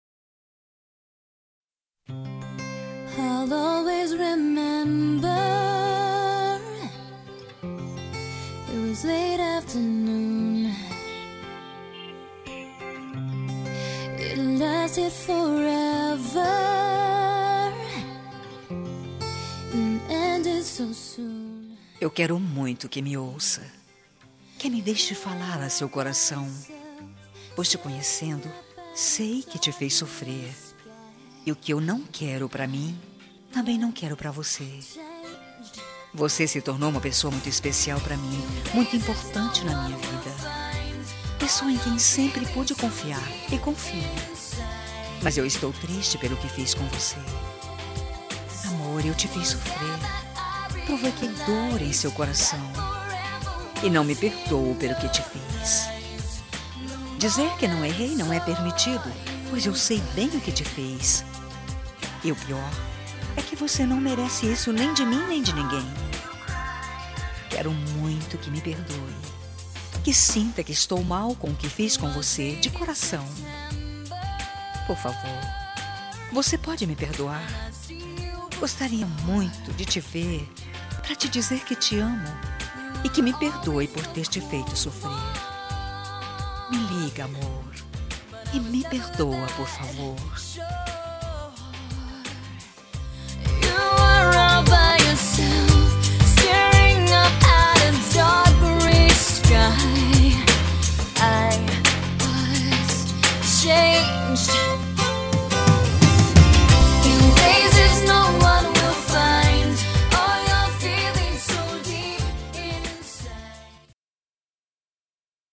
Telemensagem de Desculpas – Voz Feminina – Cód: 201810 – Linda